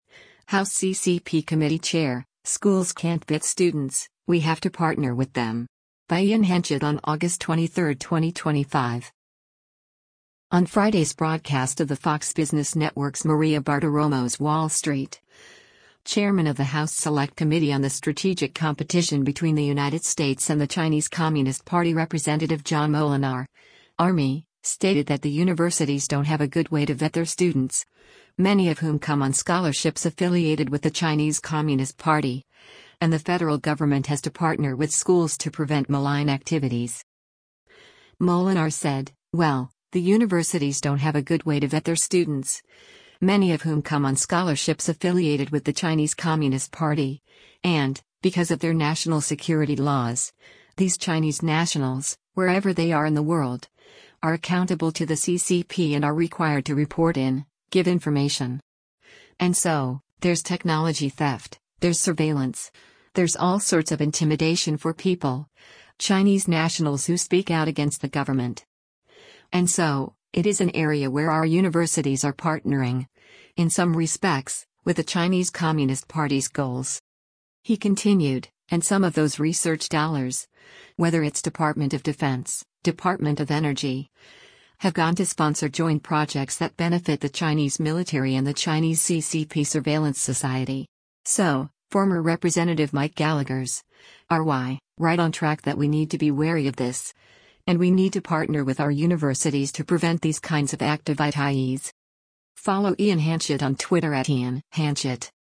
On Friday’s broadcast of the Fox Business Network’s “Maria Bartiromo’s Wall Street,” Chairman of the House Select Committee on the Strategic Competition Between the United States and the Chinese Communist Party Rep. John Moolenaar (R-MI) stated that “the universities don’t have a good way to vet their students, many of whom come on scholarships affiliated with the Chinese Communist Party,” and the federal government has to “partner” with schools to prevent malign activities.